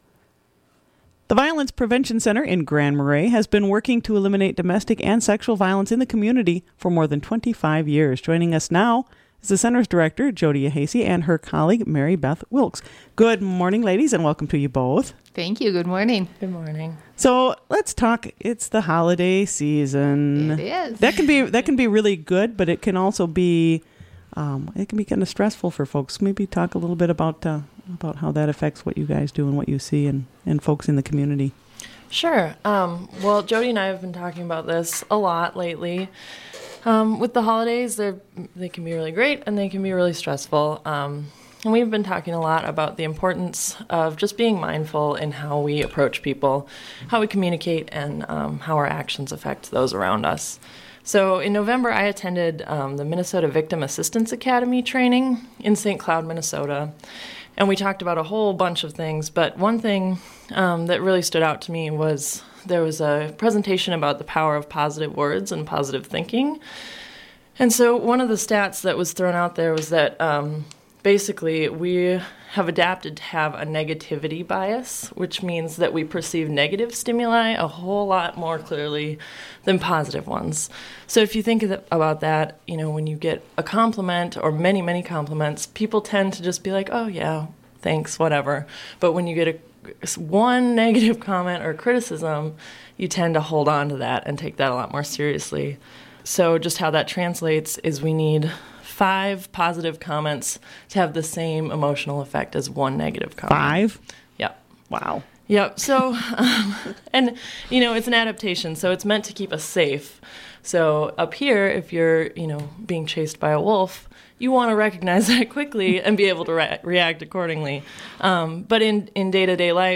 Violence Prevention Center reminds us of the power of positive thinking | WTIP North Shore Community Radio, Cook County, Minnesota